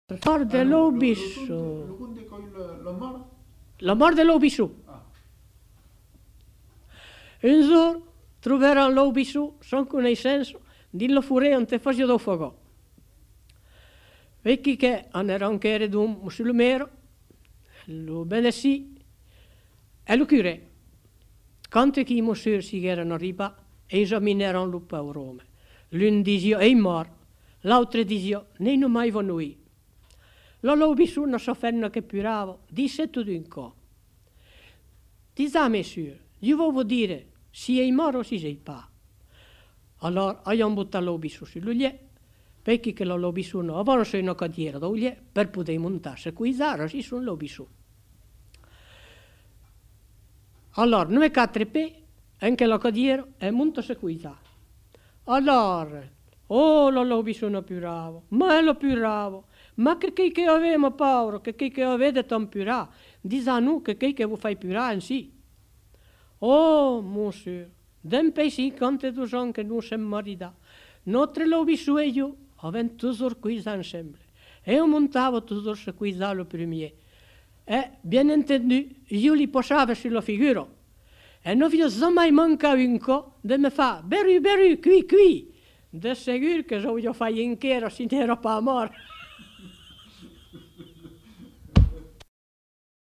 Aire culturelle : Périgord
Lieu : Saint-Amand-de-Vergt
Genre : conte-légende-récit
Type de voix : voix de femme
Production du son : parlé